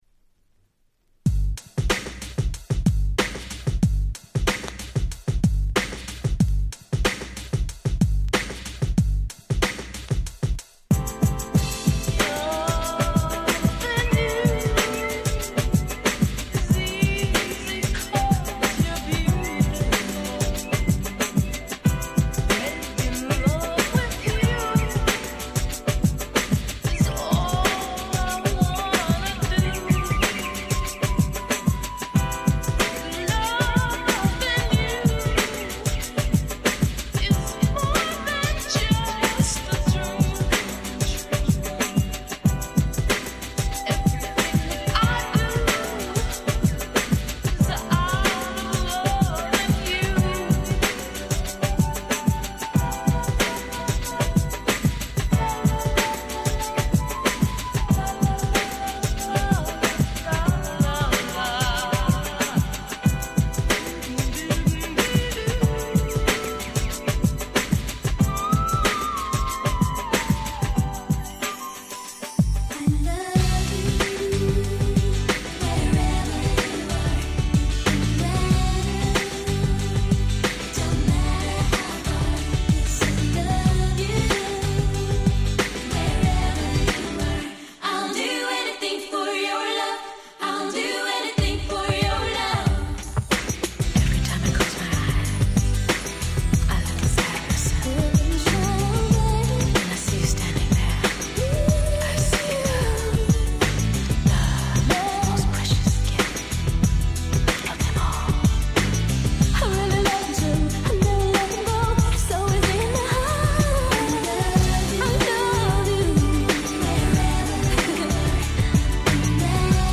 【Media】Vinyl 12'' Single